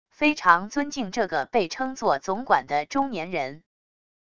非常尊敬这个被称作总管的中年人wav音频生成系统WAV Audio Player